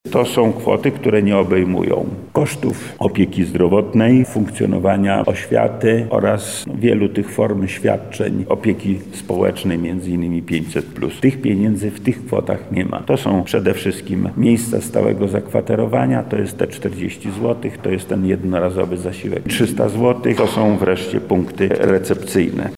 Od początku wojny z poziomu Urzędu Wojewódzkiego wydaliśmy 267 mln 678 tys. zł – mówi wojewoda lubelski Lech Sprawka: